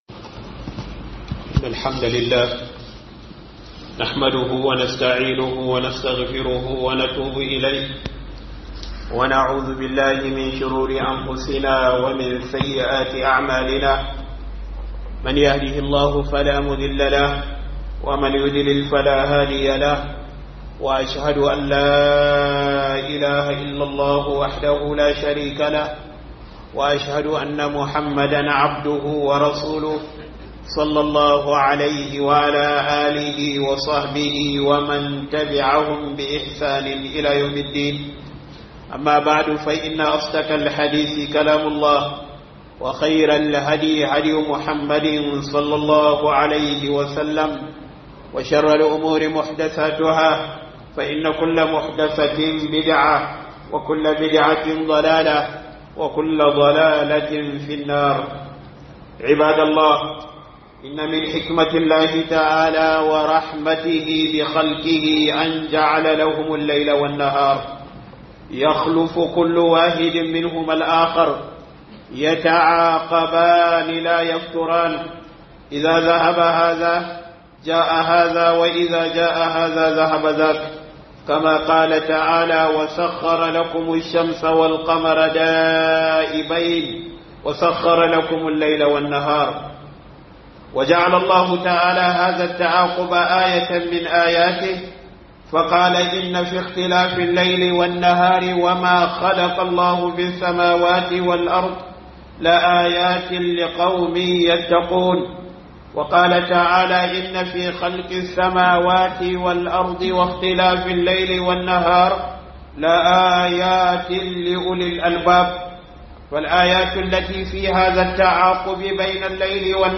Izina daga Shudewar Dare da Yini - Huduba